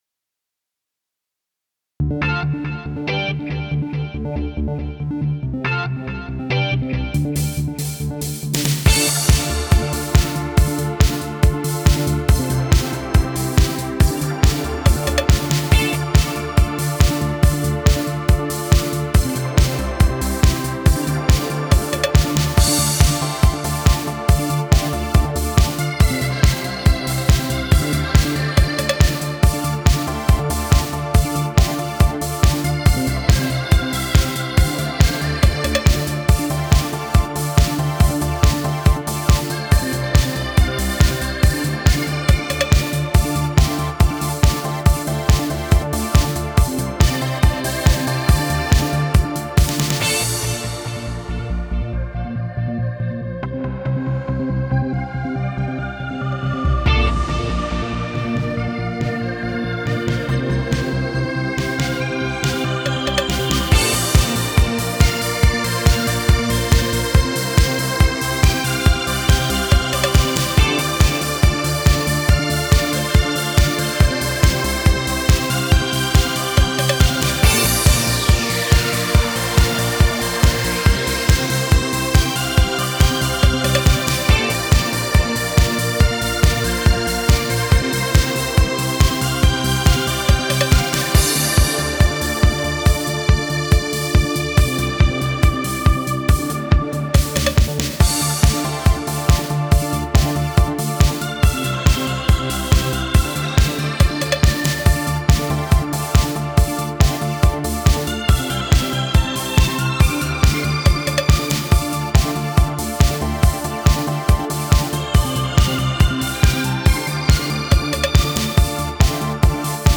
EDM-трек, но там пара гитарных аккордов с дилэем несколько раз повторялась.
Был далеко от дома, поэтому сводить пришлось на старых наушниках от iPhone, EarPods 3,5мм которые.